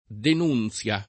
den2nZLa] s. f. — anche (e oggi più com.) denuncia [den2n©a]; pl. -ce o -cie — raro denunciazione [